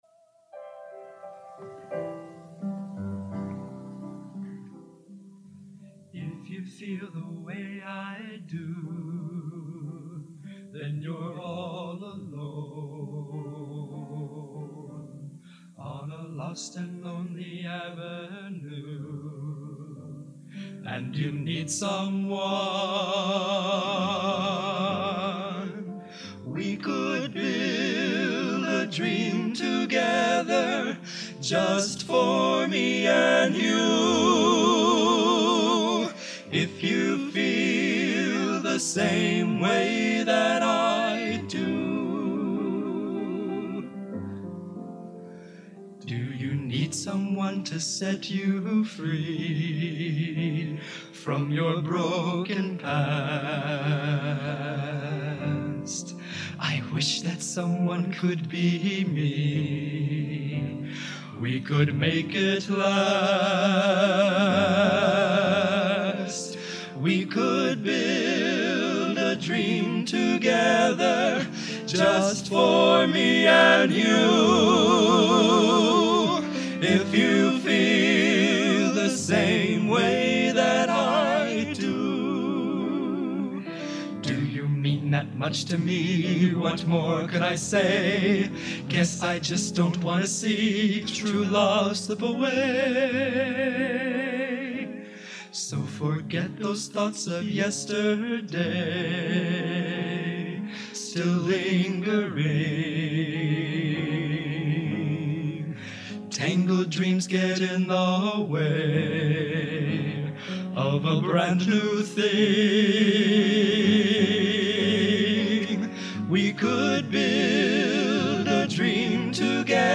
Genre: Popular / Standards Schmalz | Type:
Soft Trio